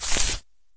default_grass_footstep.2.ogg